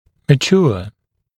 [mə’ʧuə][мэ’чуа]зрелый, развившийся; созревать